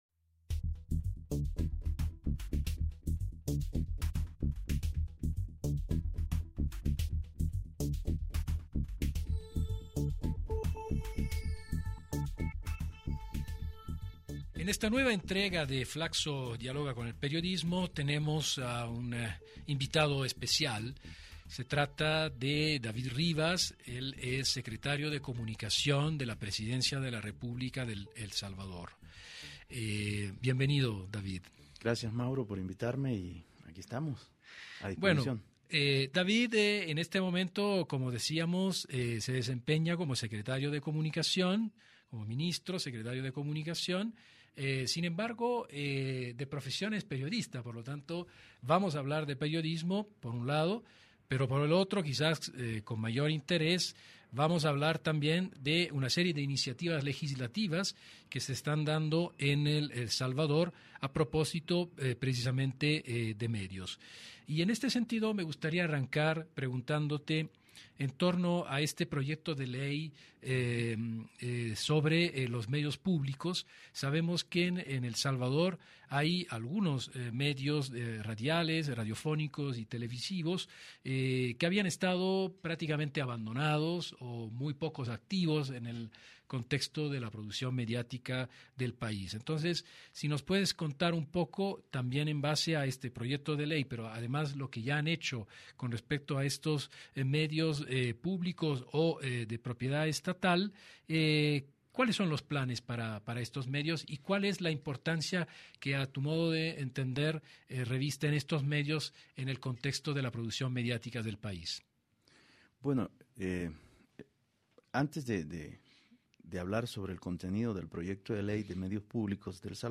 A nuestros estudios de radio llegó David Rivas, Secretario de Comunicación de Presidencia de la República del Salvador. Conversamos sobre proyecto de Ley de Medios Públicos en ese país, ¿Es necesario darle el carácter público a los medios gubernamentales?, programación enlatada, reconceptualización de medios oficialistas, ¿Cómo se concibe una televisión pública?, agenda invisibilizada, pluralidad de medios, entre otros.